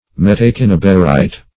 Search Result for " metacinnabarite" : The Collaborative International Dictionary of English v.0.48: Metacinnabarite \Met`a*cin"na*bar*ite\, n. [Pref. meta- + cinnabar.] (Min.) Sulphide of mercury in isometric form and black in color.